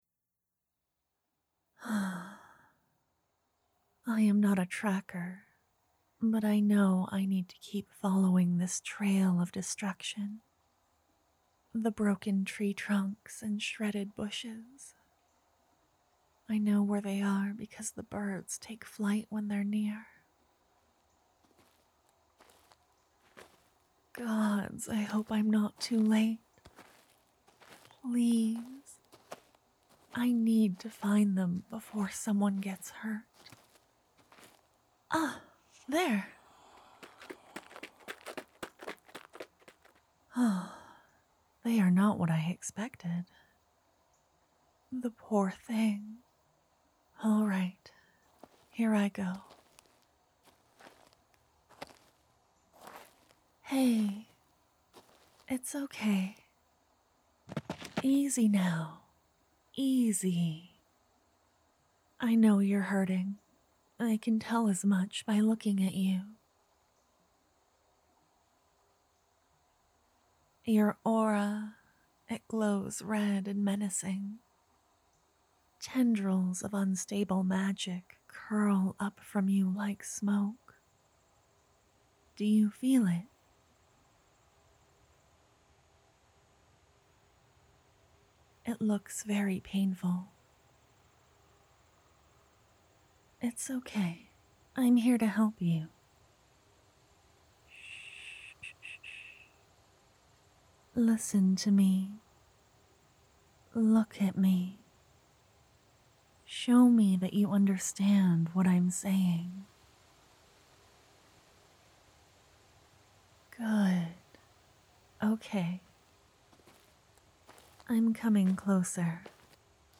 This story comes from a prompt given by one of My Hearts, it was a wide open concept but I wanted to do something comforting and peaceful~